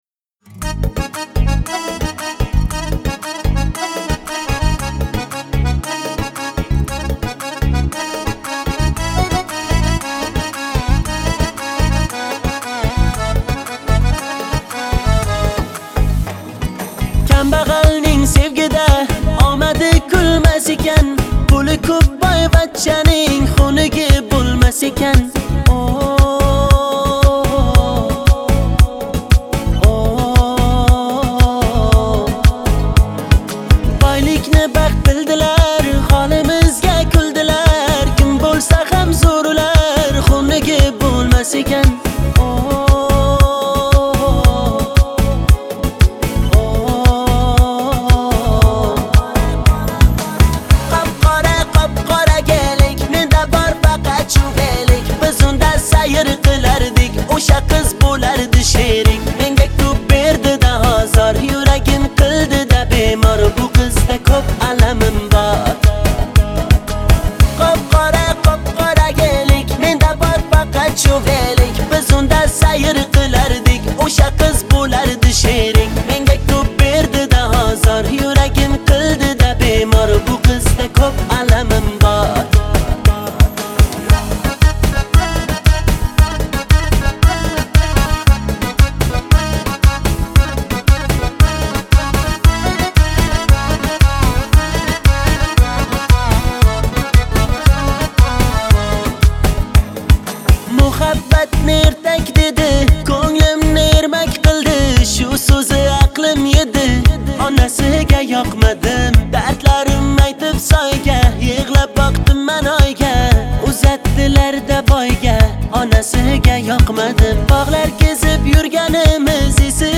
ژانر: پاپ و رپ
شاد ترکیه ای معروف